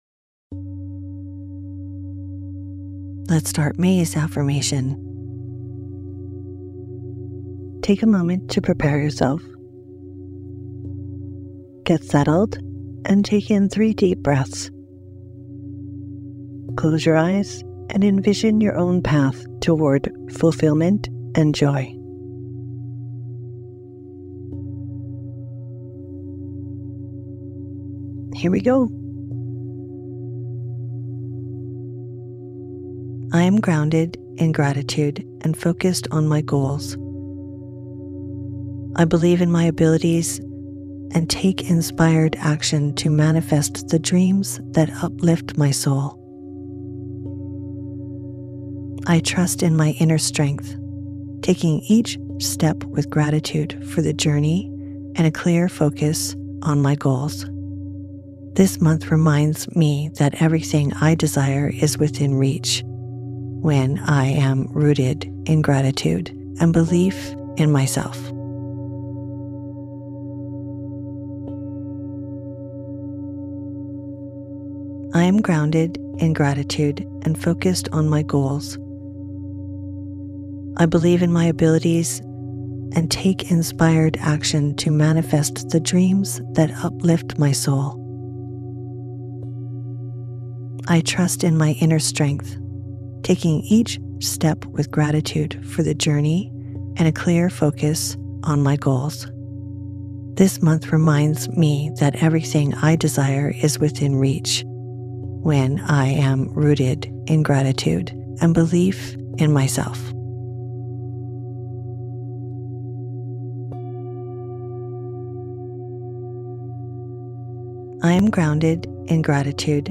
Sleep versions feature the affirmation repeated three times, creating repetition for deeper impact and greater benefits.